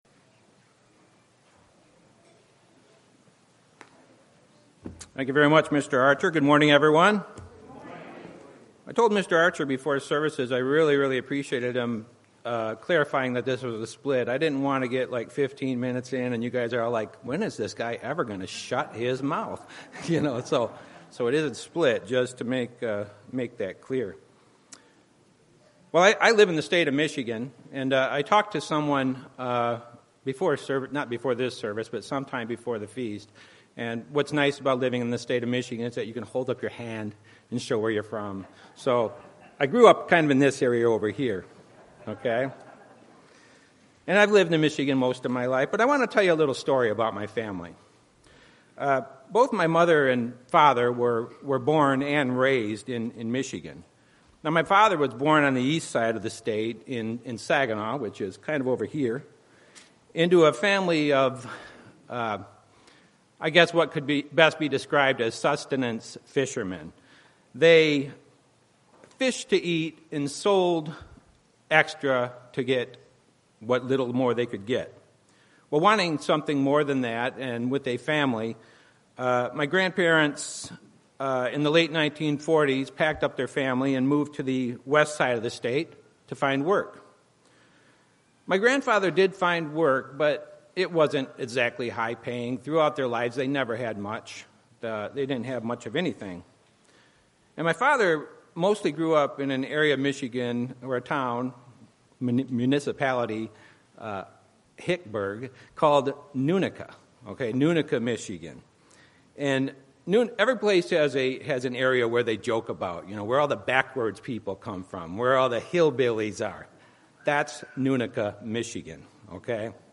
This sermon was given at the Phoenix, Arizona 2016 Feast site.